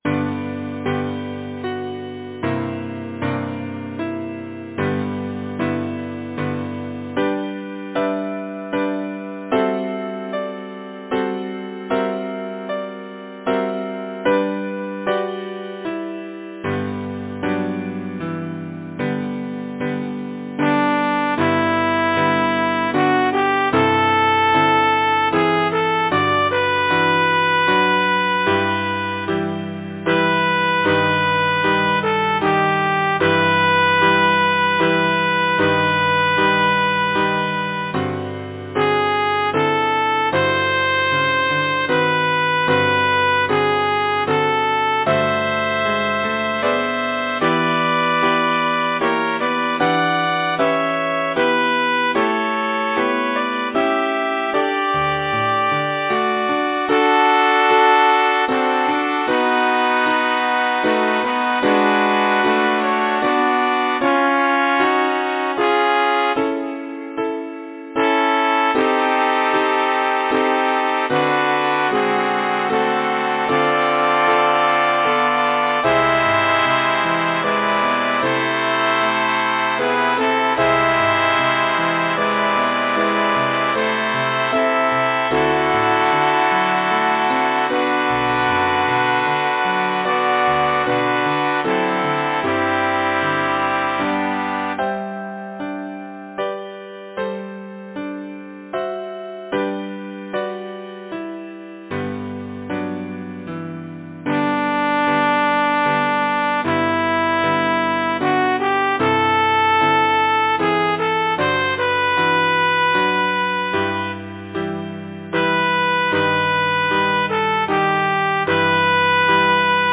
Title: The Water-Lily Composer: Frederick S. Converse Lyricist: Mary Frances Butts Number of voices: 4vv Voicing: SATB Genre: Secular, Partsong
Language: English Instruments: Piano